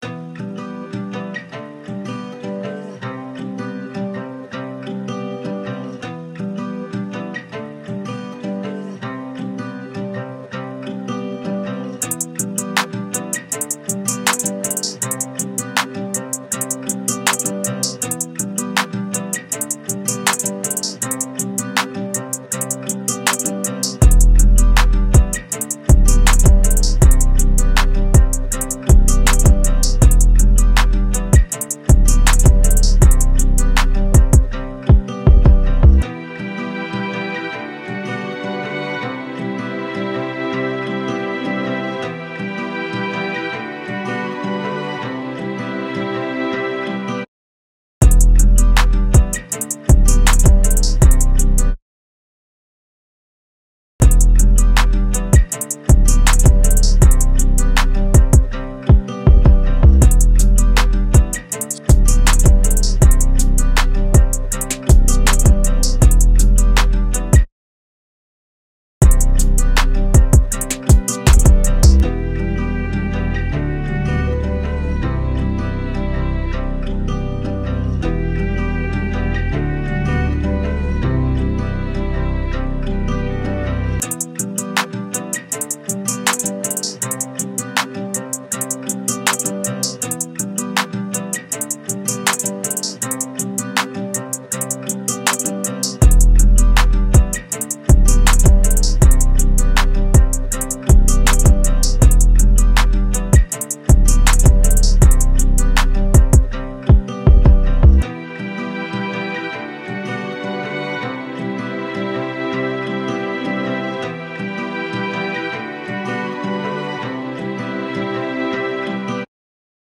R&B, Hip Hop
G Major